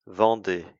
Vendée (French pronunciation: [vɑ̃de]
Vendee.ogg.mp3